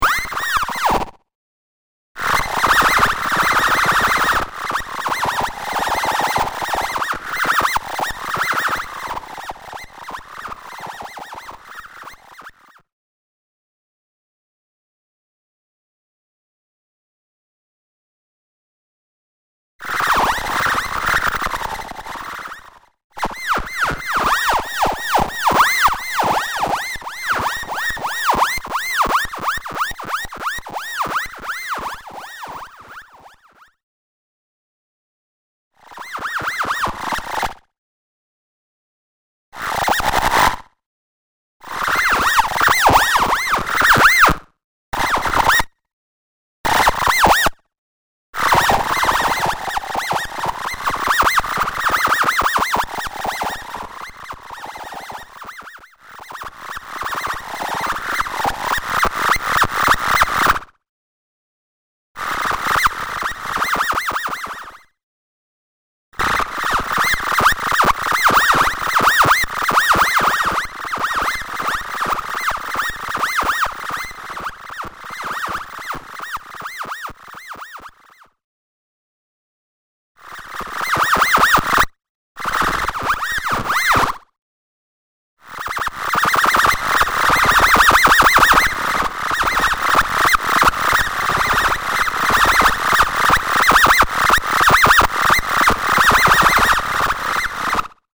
All birds sing in mono.